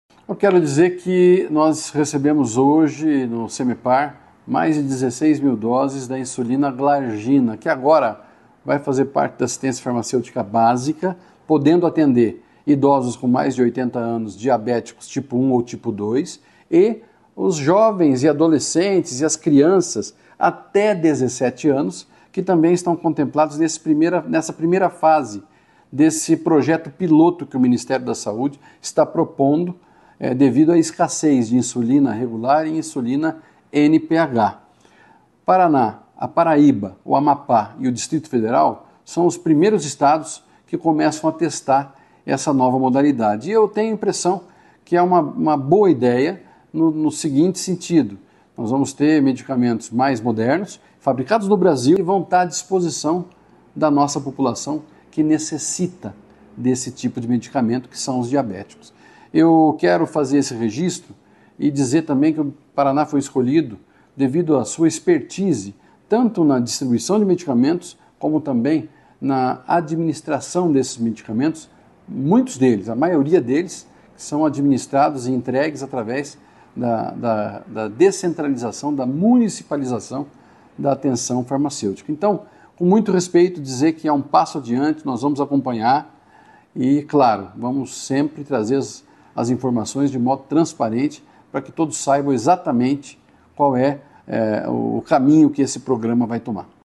Sonora do secretário da Saúde, Beto Preto, sobre as canetas reutilizáveis de insulina Glargina